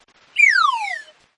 Cartoon Flute Down sound
(This is a lofi preview version. The downloadable version will be in full quality)
JM_Tesla_Lock-Sound_Cartoon-Flute-Down_Watermark.mp3